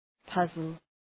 Προφορά
{‘pʌzəl}